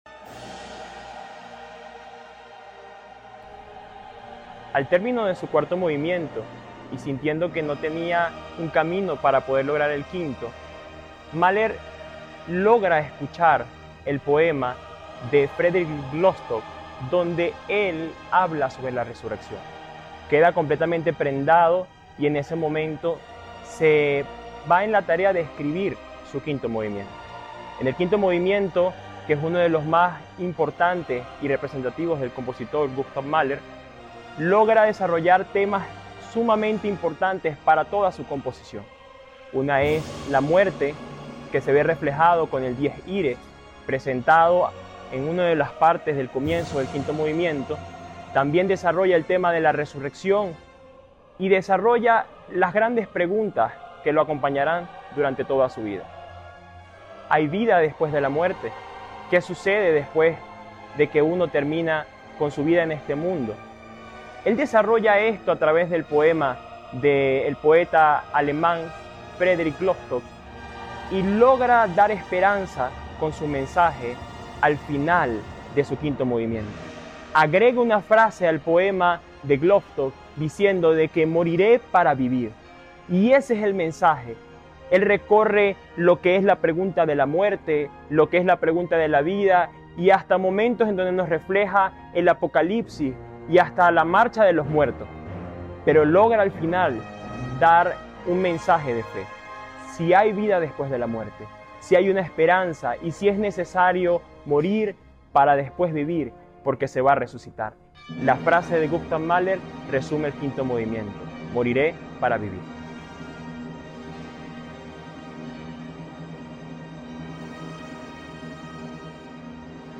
Te compartimos pequeños fragmentos de la 2da Sinfonía de G. Mahler. Te esperamos este próximo sábado 1 de marzo a las 5pm en la Sala Simón Bolivar del Centro Nacional de Acción Social por la Música.